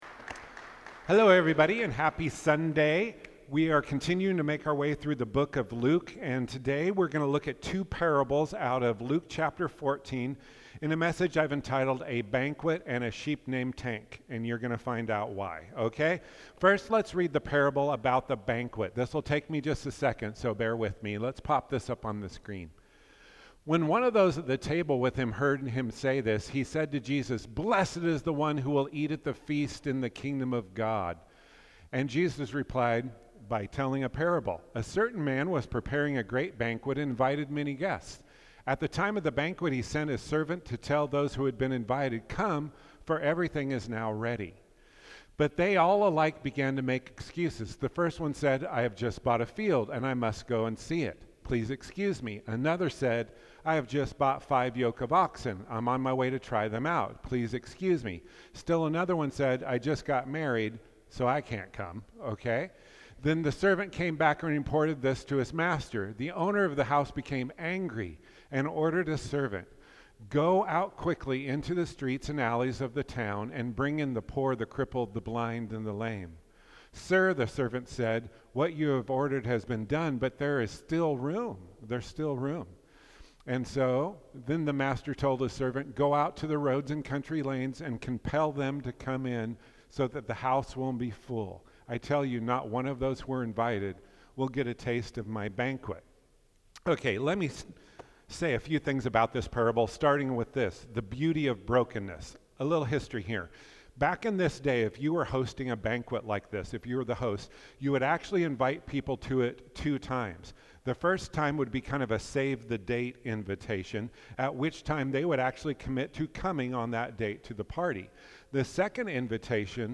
Sermons | Faith Avenue Church